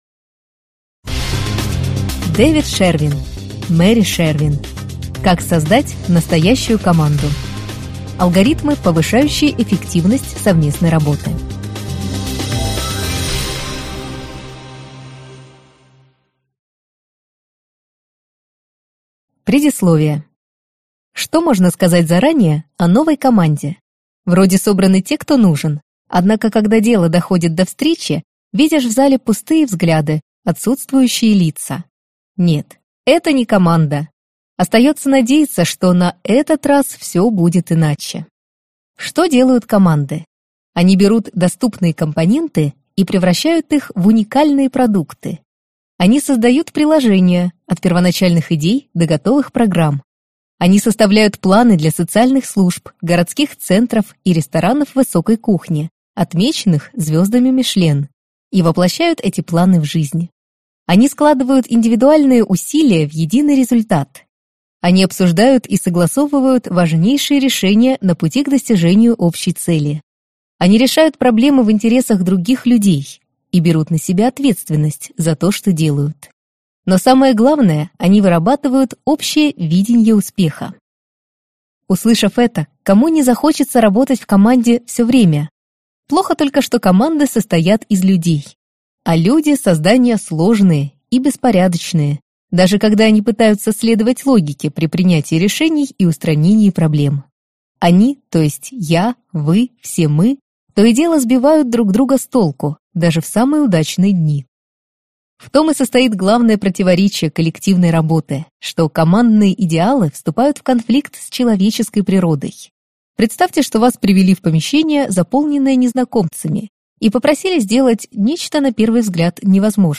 Аудиокнига Как создать настоящую команду | Библиотека аудиокниг